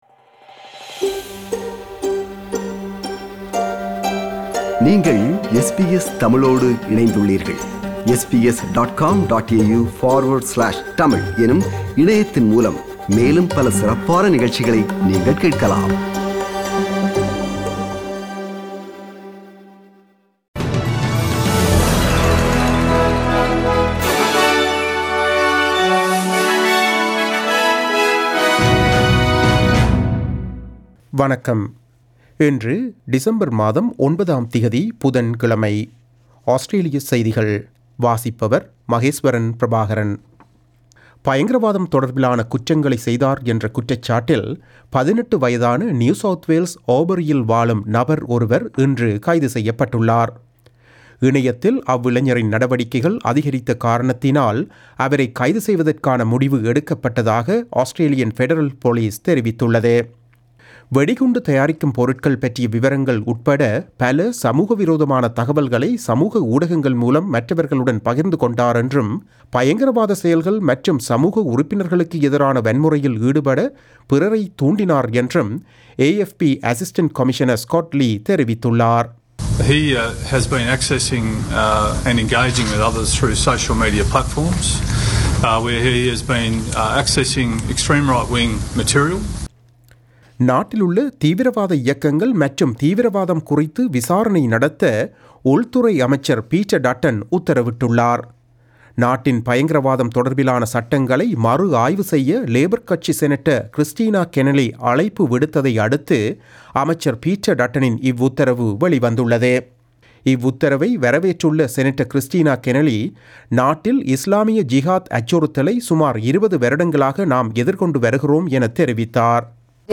Australian news bulletin for Wednesday 09 December 2020.